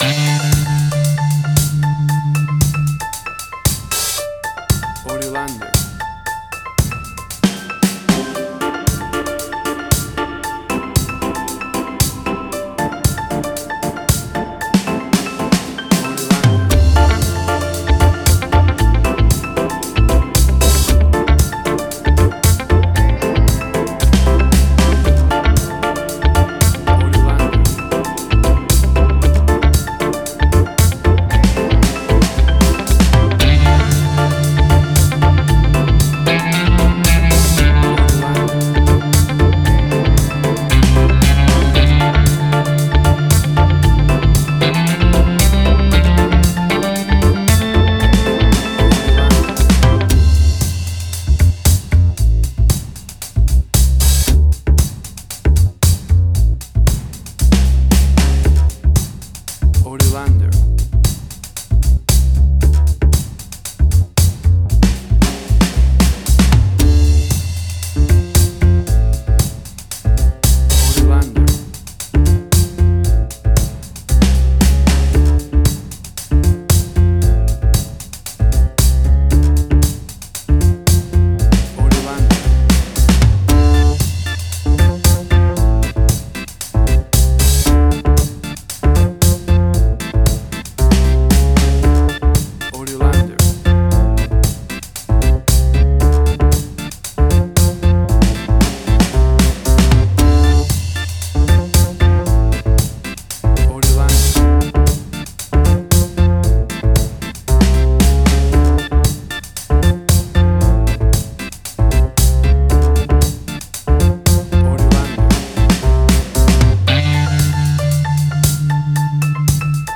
Tempo (BPM): 115